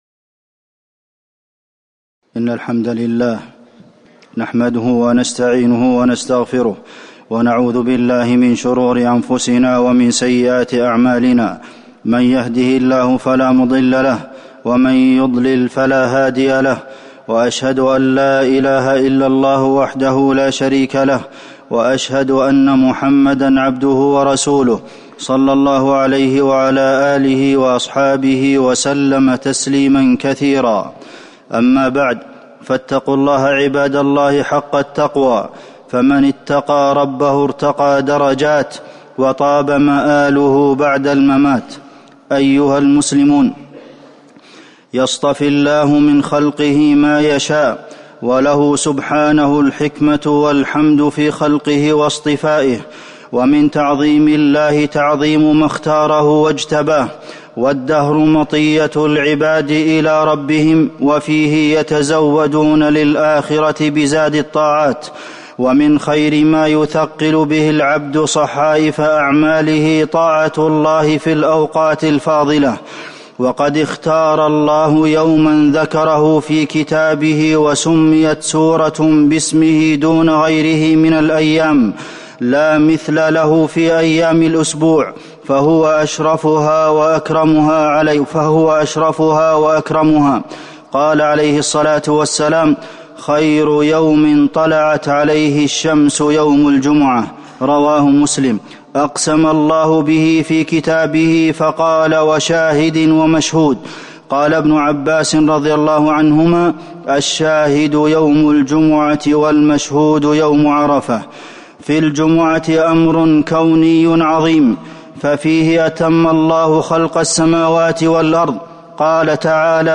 تاريخ النشر ١ رجب ١٤٤٠ هـ المكان: المسجد النبوي الشيخ: فضيلة الشيخ د. عبدالمحسن بن محمد القاسم فضيلة الشيخ د. عبدالمحسن بن محمد القاسم فضل يوم الجمعة